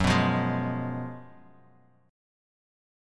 Listen to F#M7sus2sus4 strummed